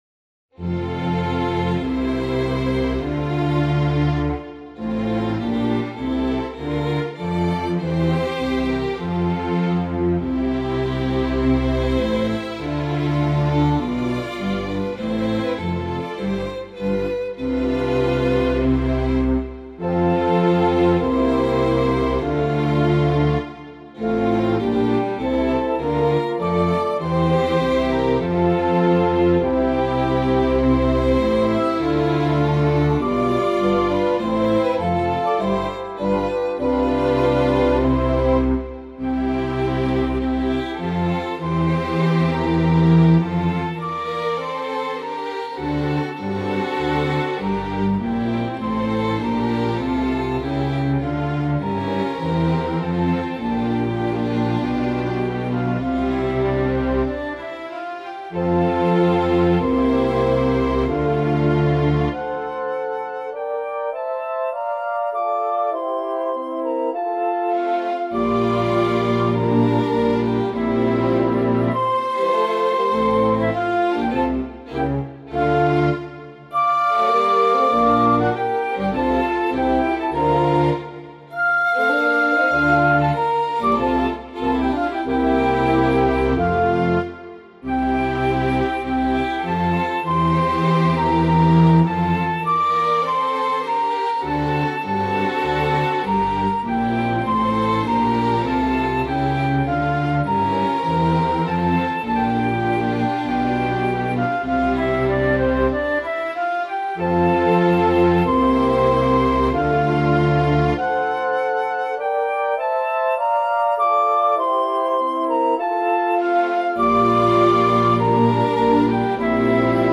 Главная » Классическая музыка